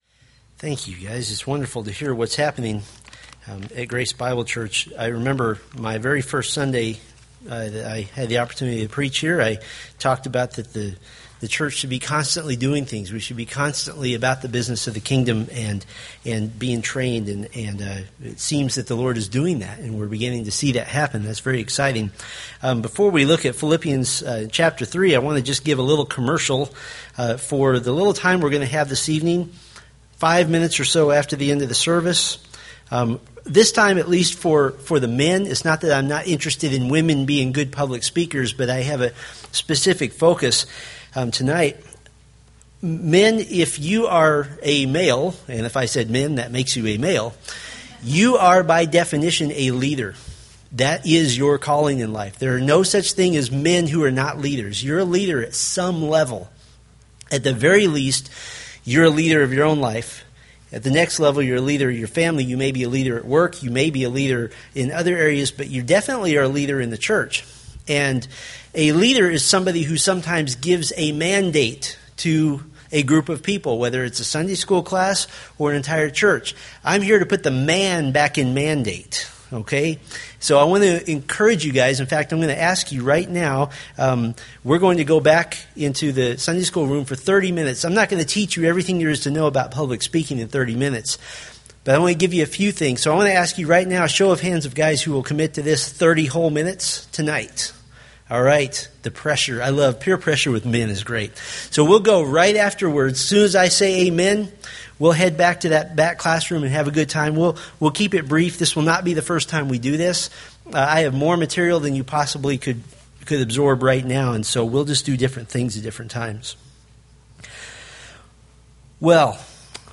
Philippians Sermon Series